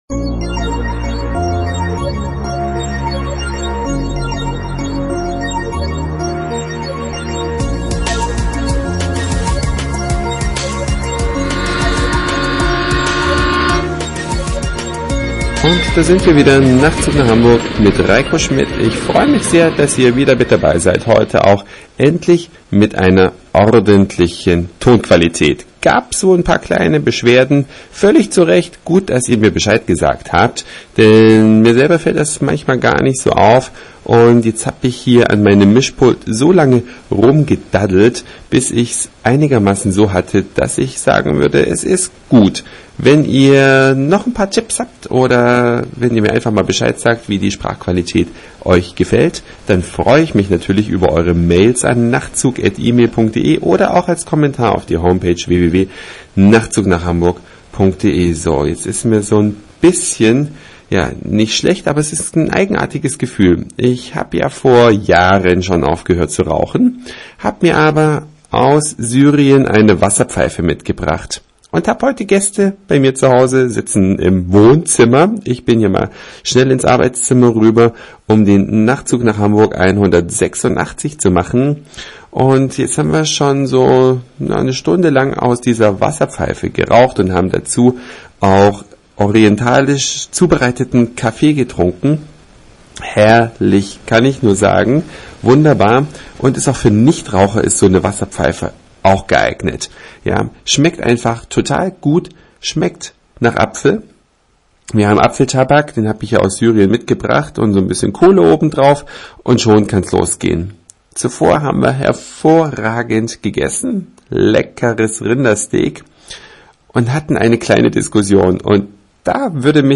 Der Nachtzug mit besserer Tonqualität :-) Die Nebenwirkungen